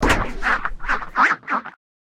duck2.ogg